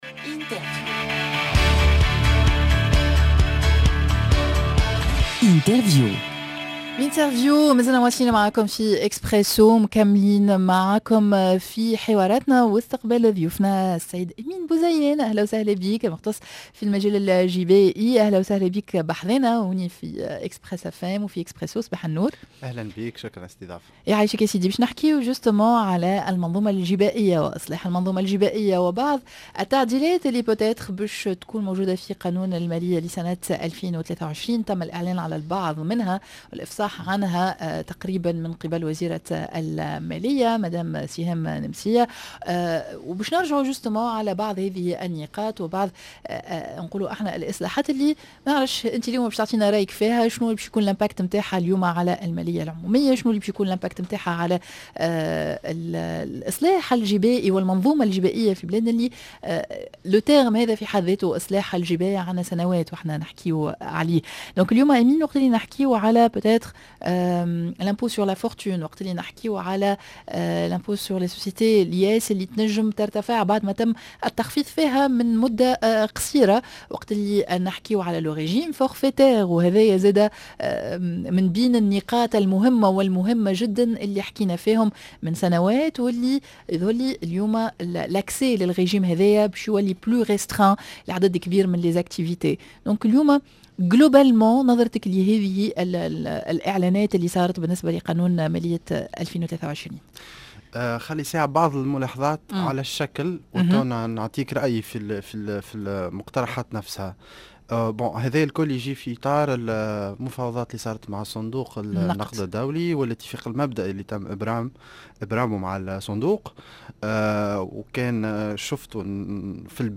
L'interview:إصلاح المنظومة الجبائيةممكن اليوم؟